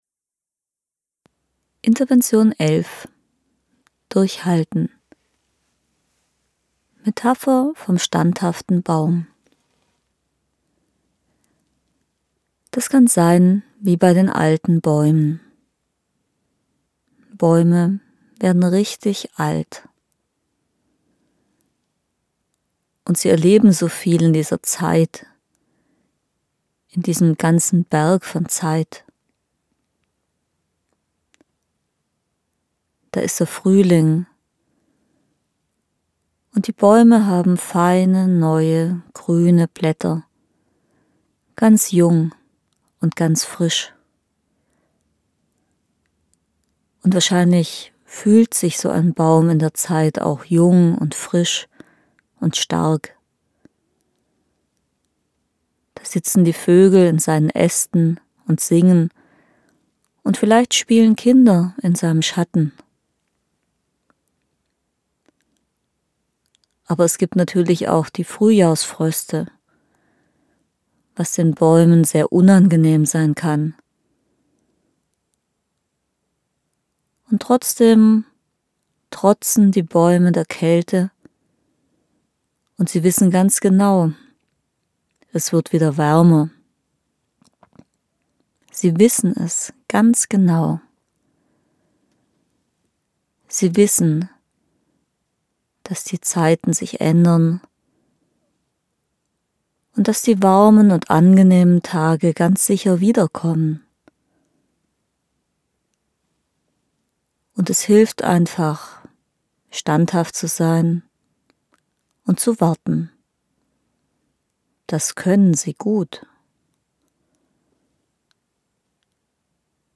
Gratis Trance zum Probehören